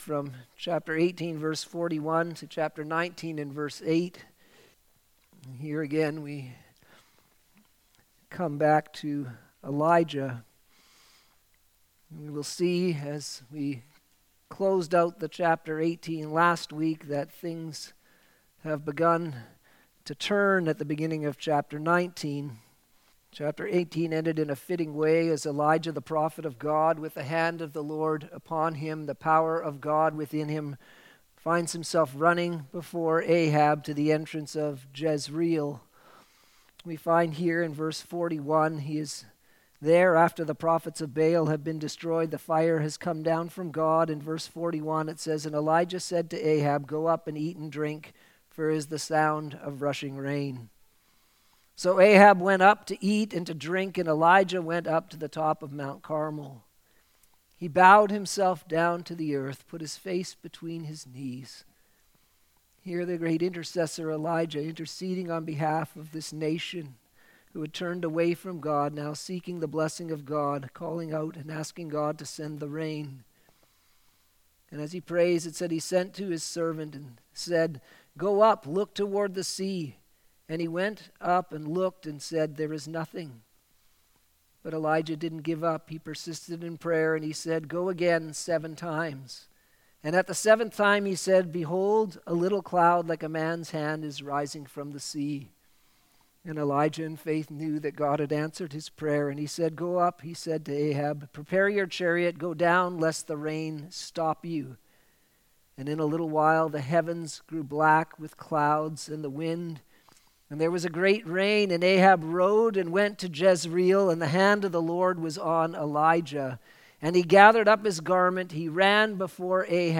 Sermons
Evening Service - 1 Kings 19: 18